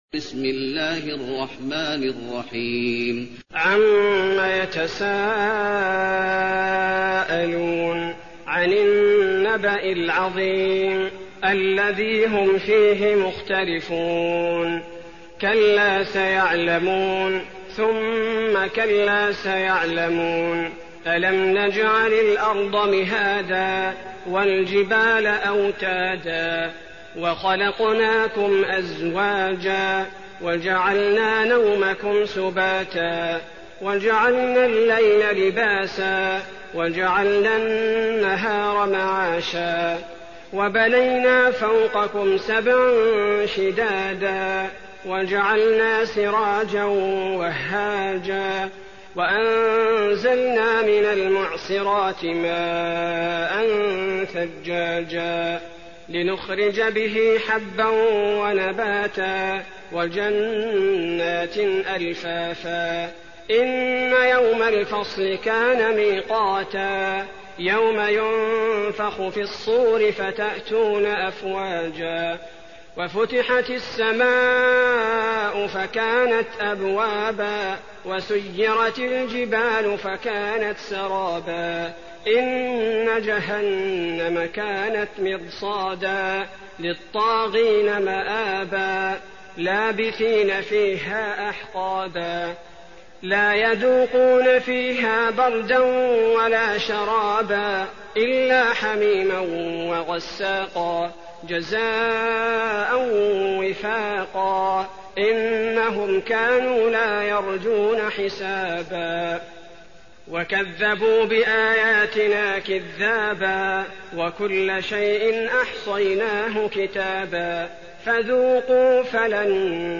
المكان: المسجد النبوي النبأ